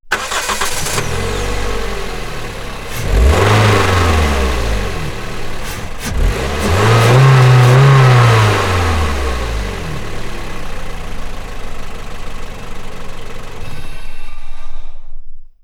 Acelerones del motor de un coche RAV4